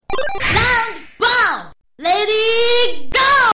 game_ready_go_woman..mp3